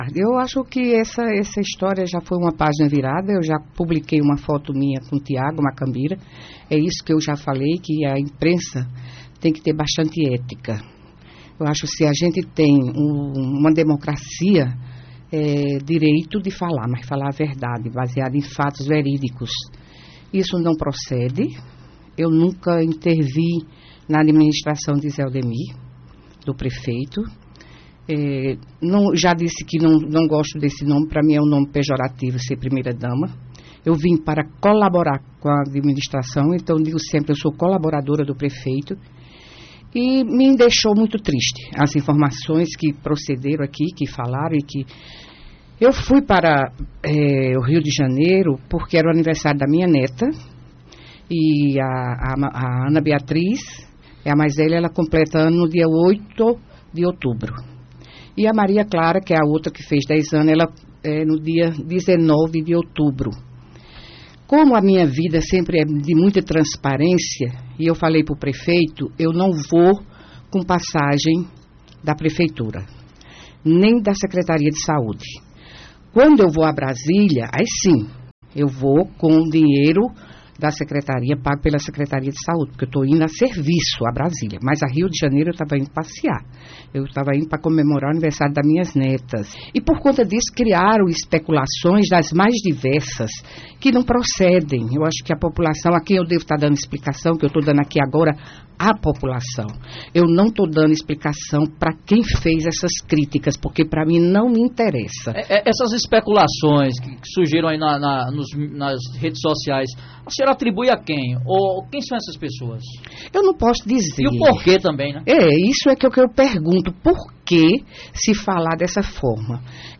Em entrevista na tarde desta terça-feira (07) no programa Rádio Vivo da Alto Piranhas.